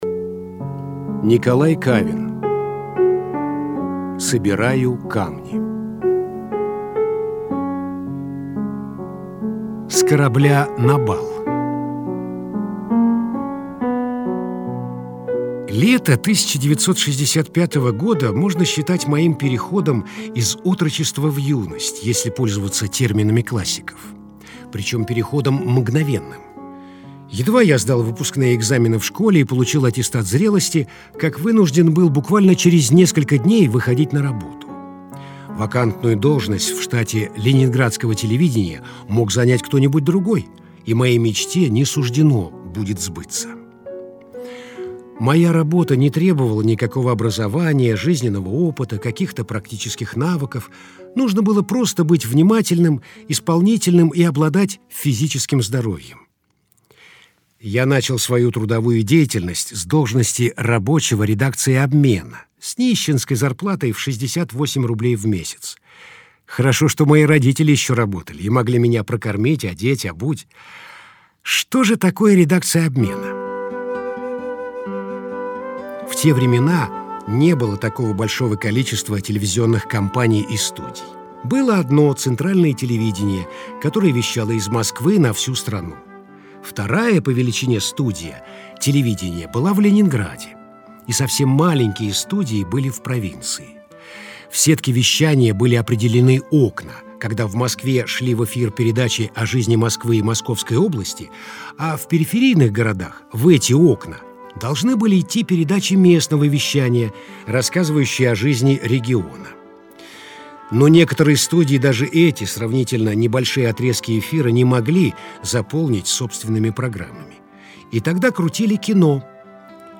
Все программы - Литературные чтения